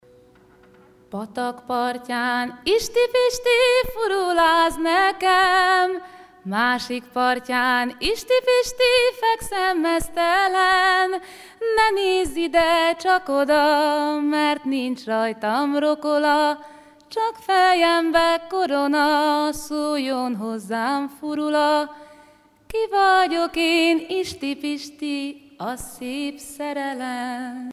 Concert a Brugges Festival, Belgique
Pièce musicale inédite